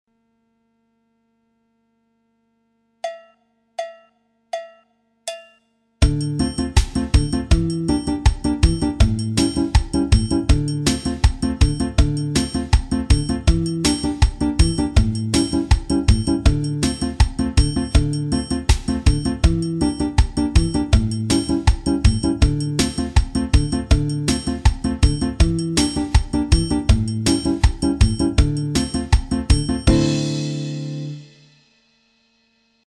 Variante 2 figure guitare xote 1,2 (midi) Téléchargez ou écoutez dans le player.
batida avec une syncope